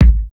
pcp_kick02.wav